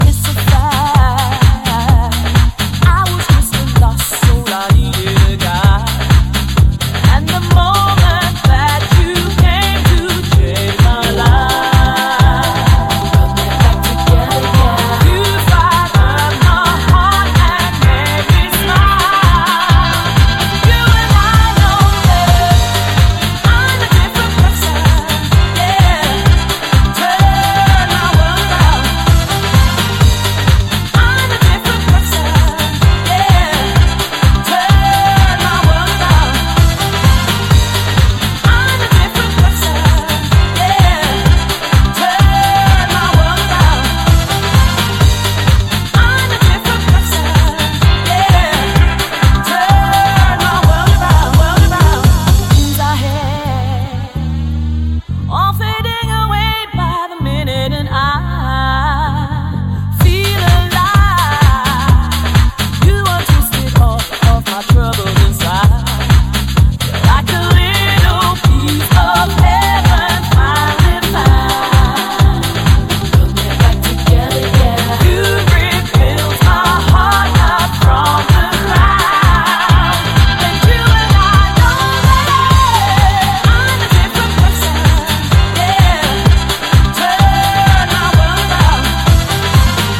modern day house classic